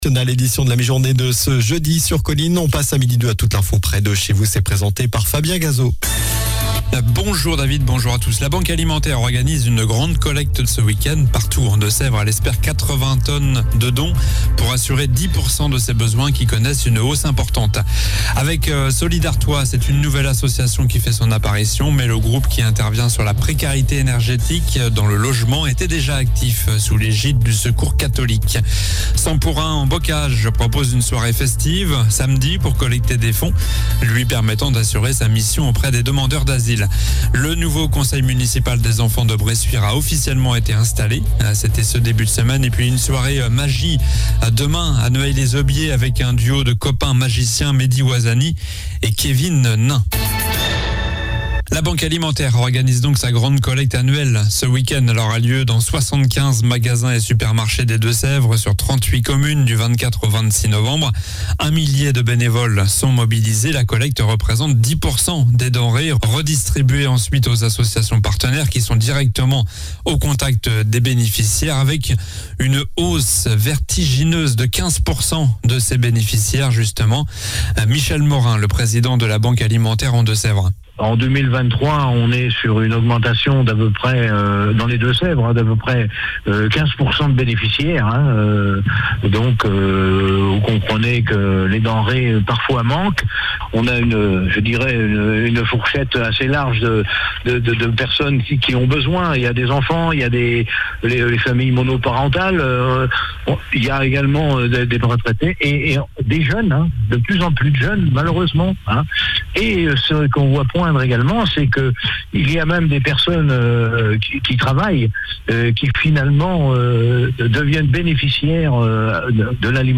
Journal du jeudi 23 novembre (midi)